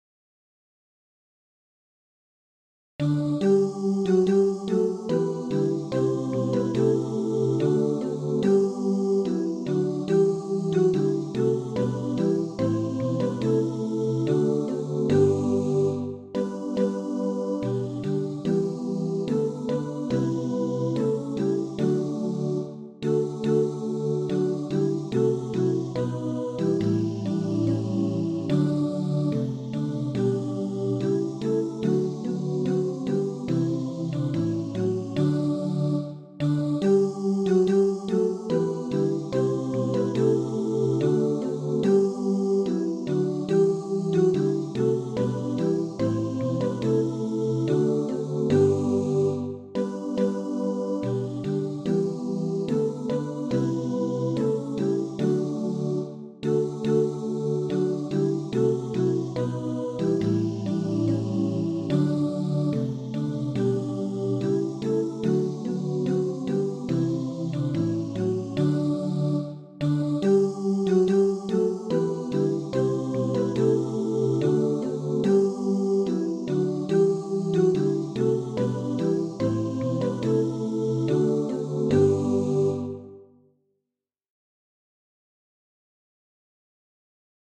3-5 stämmig blandad kör med eller utan ackompanjemang.
Trad. folkvisa från Västmanland